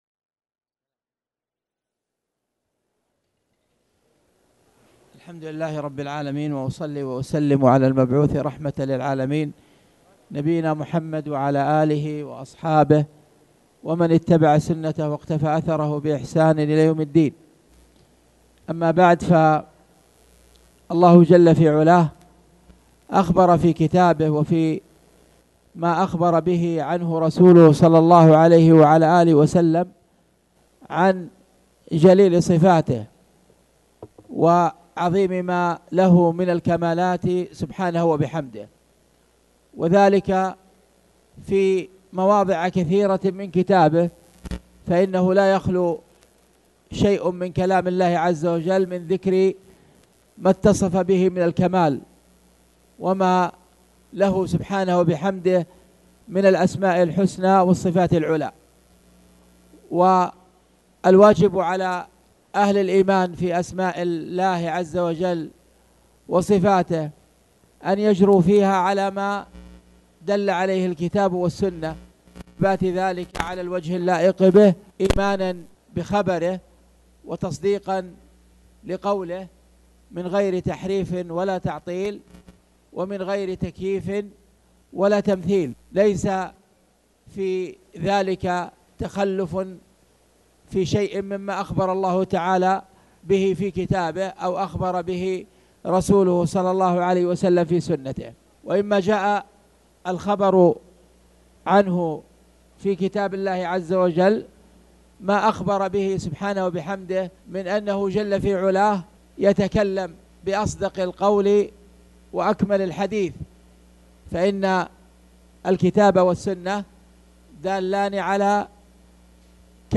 تاريخ النشر ٢٧ ربيع الثاني ١٤٣٩ هـ المكان: المسجد الحرام الشيخ